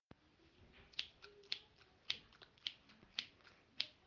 Geräuschebox
Die verschiedenen Geräusche wurden von den Kindern der zweiten Klassen in Finnentrop und Bamenohl während eines Musikprojektes im Mai 2022 gesammelt.
Schere.mp3